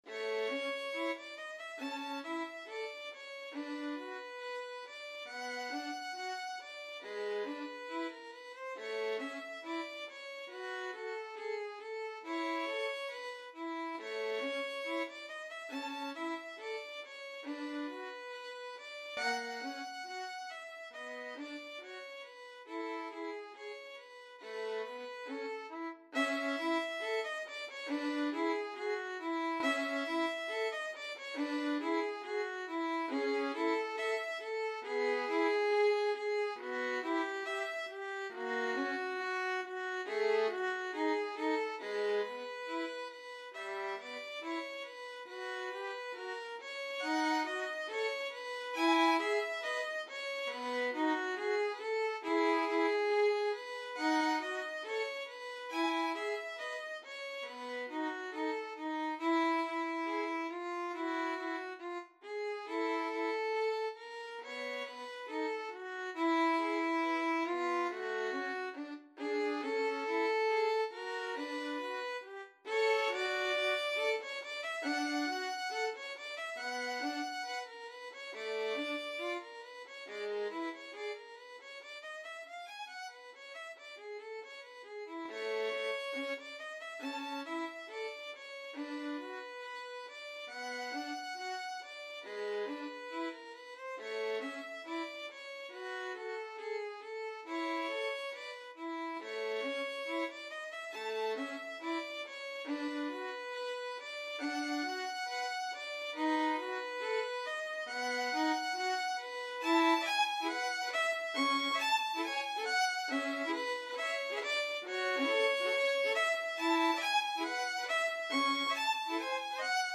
2/4 (View more 2/4 Music)
~ = 69 Allegro grazioso (View more music marked Allegro)
Classical (View more Classical Violin Duet Music)